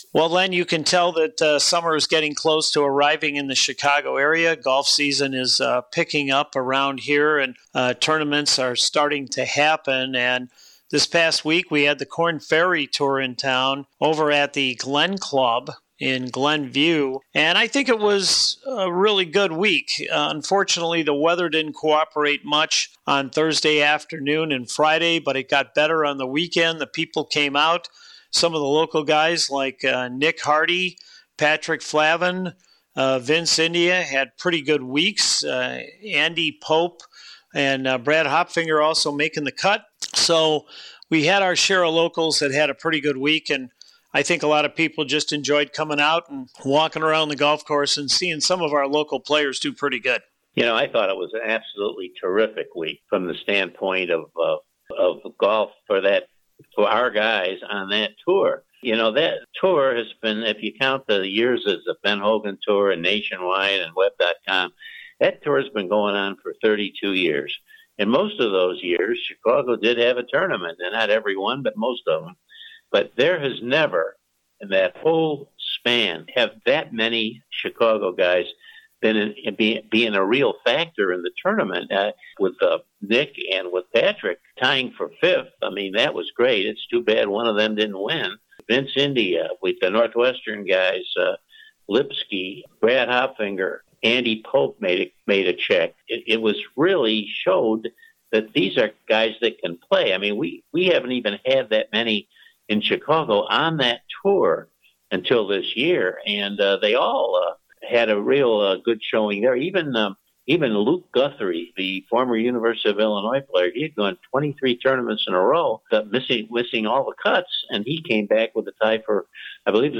From the PGA Village studios.